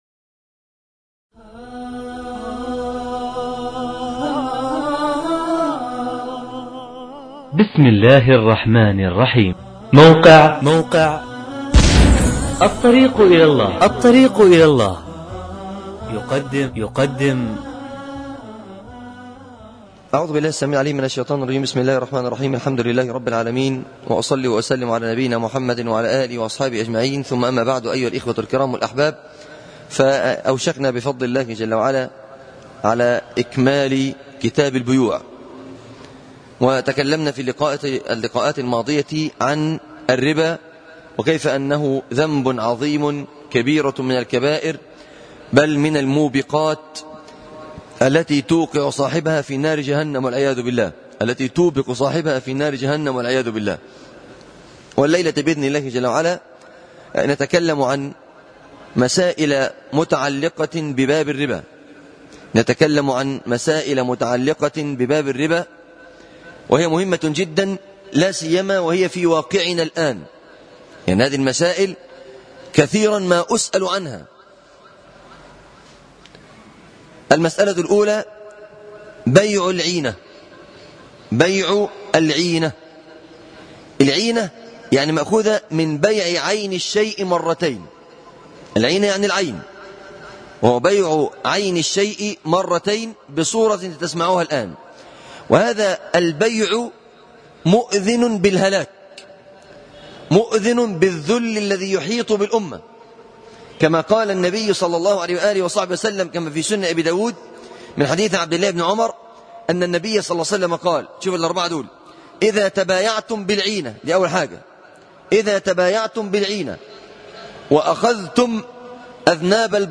سلسلة فقه البيوع - الدرس السابع عشر : الربا -الجزء الثالث-